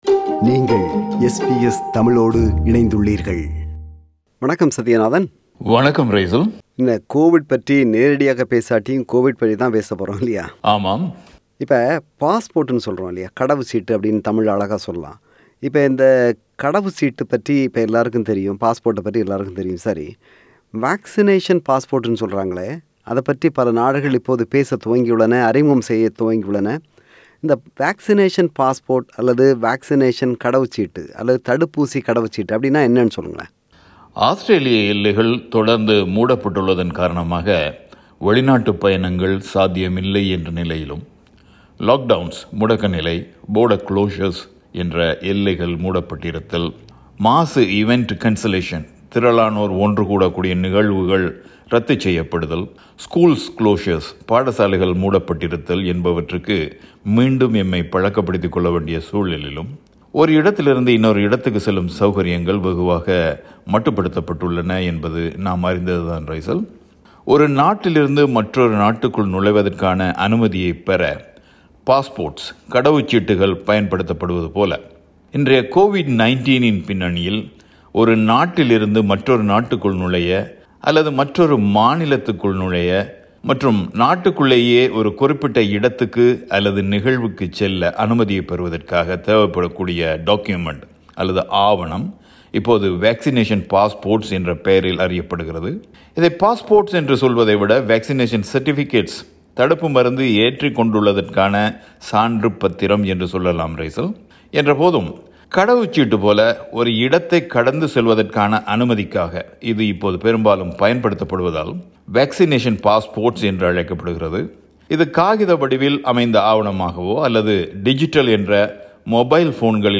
பல நாடுகளில் அறிமுகம் செய்யப்பட்டுள்ள Vaccination Passport திட்டம் மிக விரைவில் ஆஸ்திரேலியாவிலும் அறிமுகம் செய்யப்படும் என்று எதிர்பார்க்கப்படுகிறது. இந்த பின்னணியில் Vaccination Passport பற்றிய விரிவான விளக்கத்தை முன்வைக்கிறார் பிரபல வானொலியாளர்